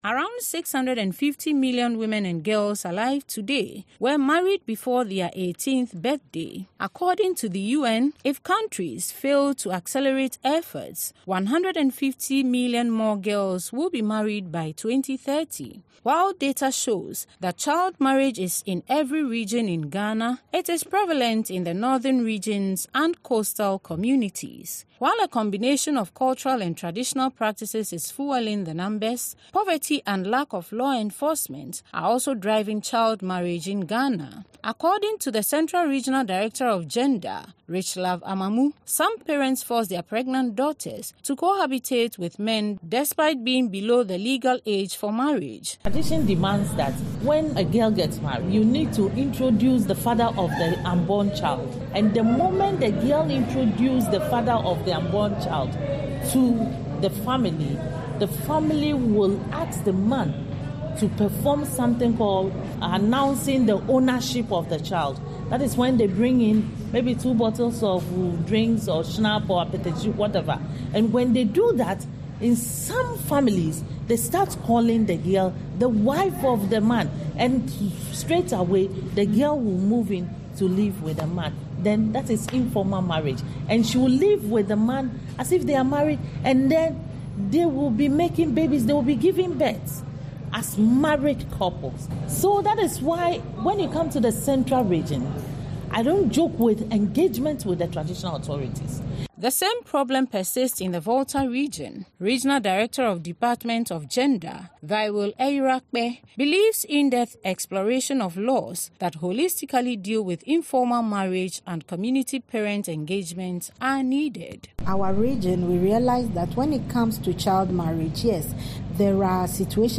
In this special report